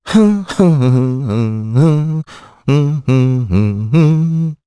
Mitra-Vox_Hum_jp.wav